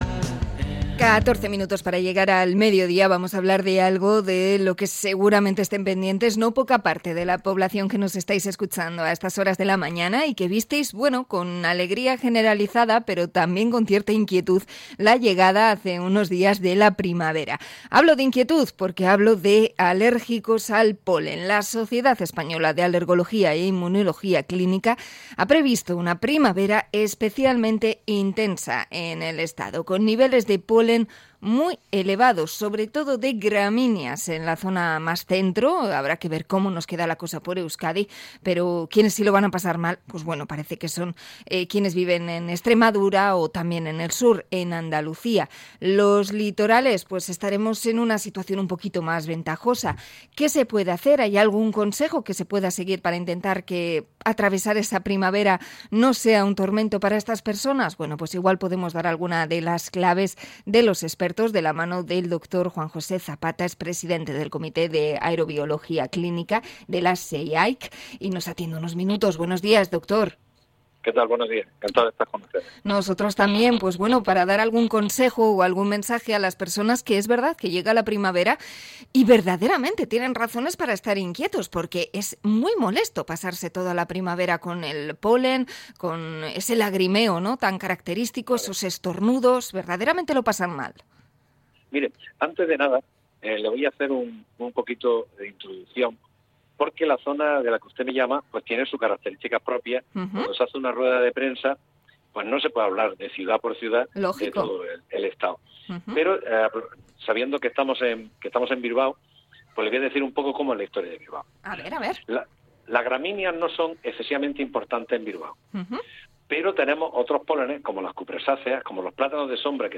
Entrevista a la sociedad española de alergología